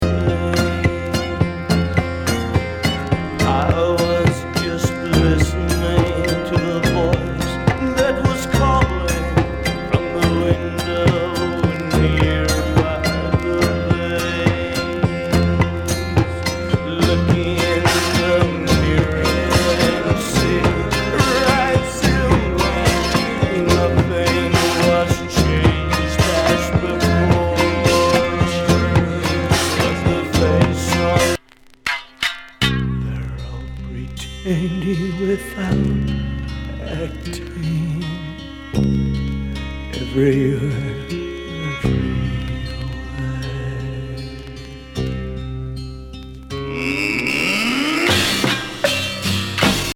海外志向の国産本格派サイケデリック・グループ72年作。エキゾチック
極東和サイケ
スピリチュアル